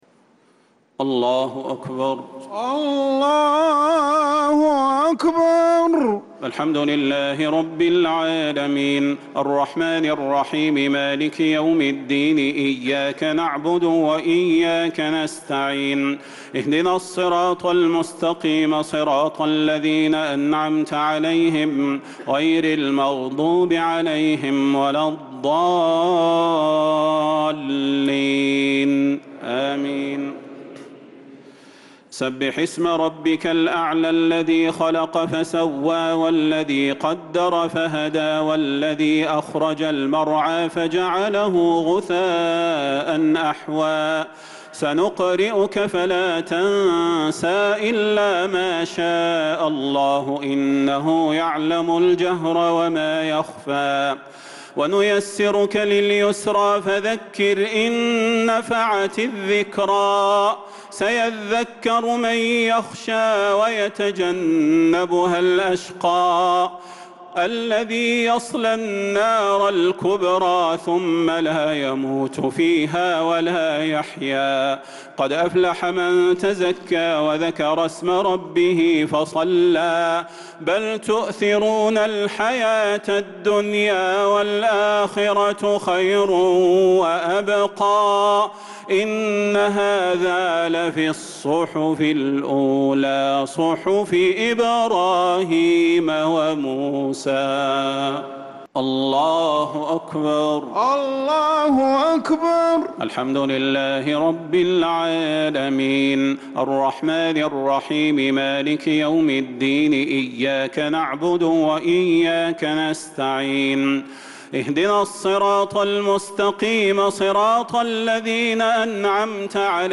صلاة الشفع و الوتر ليلة 23 رمضان 1446هـ | Witr 23rd night Ramadan 1446H > تراويح الحرم النبوي عام 1446 🕌 > التراويح - تلاوات الحرمين